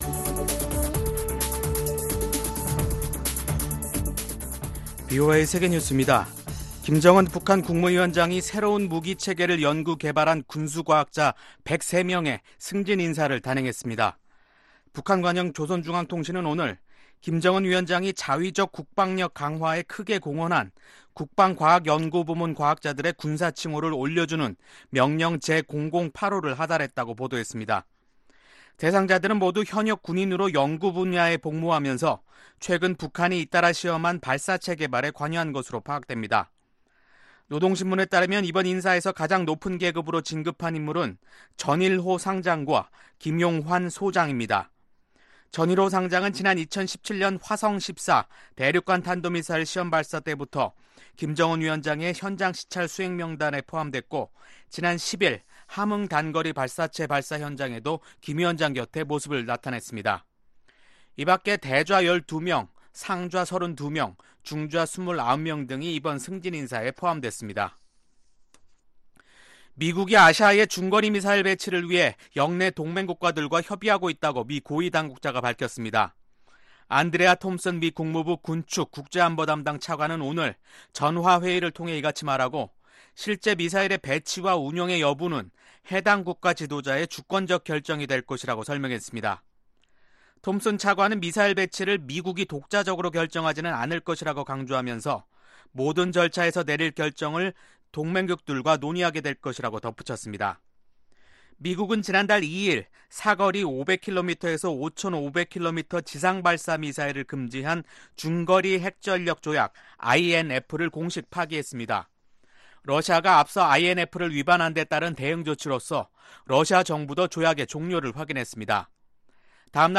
VOA 한국어 간판 뉴스 프로그램 '뉴스 투데이', 2019년 8월 13일 3부 방송입니다. 북한 관영매체들은 김정은 국무위원장이 새 무기체계를 완성한 과학자 100여명을 승진시켰다고 보도했습니다. 미국 의원들은 북한의 최근 일련의 미사일 발사에 우려를 표명하고, 트럼프 행정부가 미사일 위협 관리에 실패했다고 비판했습니다.